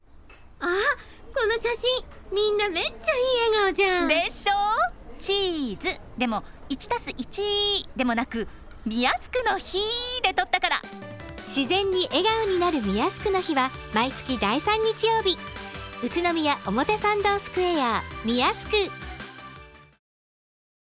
BERRY GOOD CM 2024 ｜ RADIO BERRY FM栃木